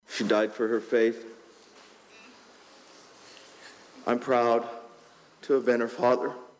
HUNDREDS GATHERED THIS (FRIDAY) MORNING FOR A PRAYER SERVICE AT THE CORNERSTONE CHURCH IN AMES WHERE THE TWO WOMEN WERE SHOT AND KILLED. THURSDAY NIGHT.